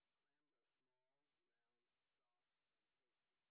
sp21_white_snr10.wav